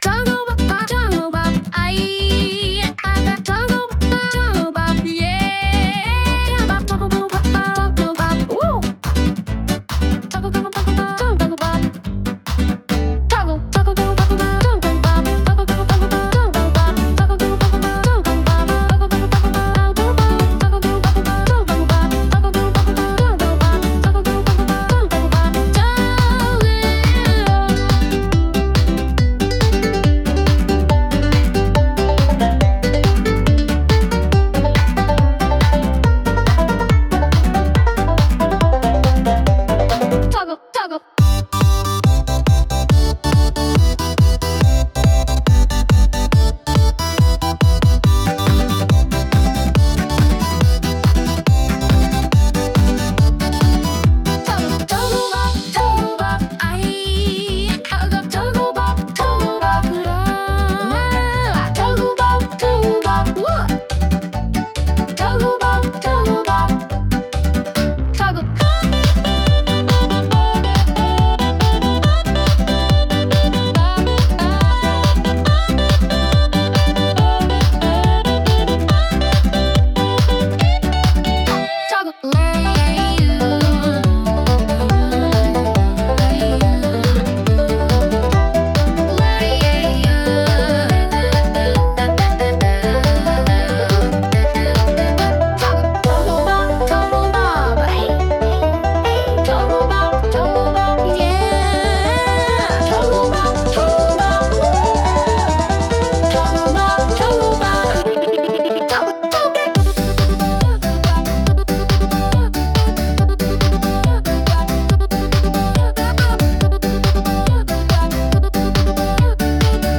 Sung by Suno
Youthful_K7_(Remix)-2_mp3.mp3